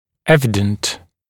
[‘evɪdənt] [‘эвидэнт] очевидный, ясный, явный, наглядный